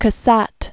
(kə-săt), Mary Stevenson 1844?-1926.